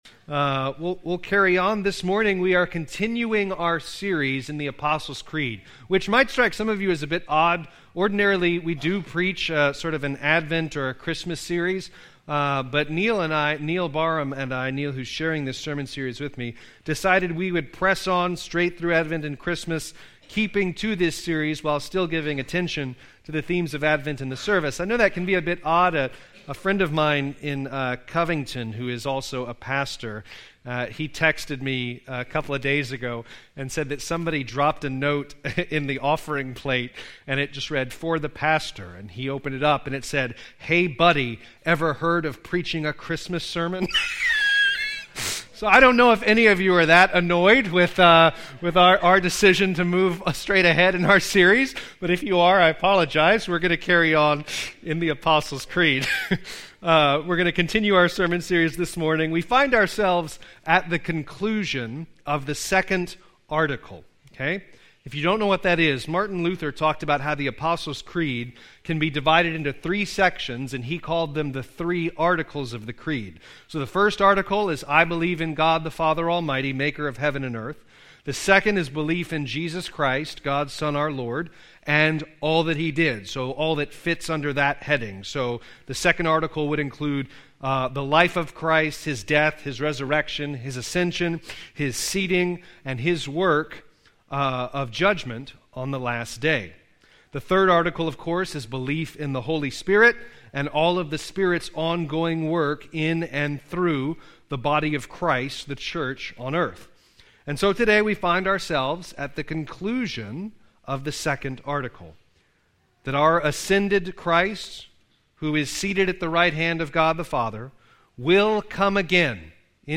Sermons by Grace Presbyterian Church - Alexandria, LA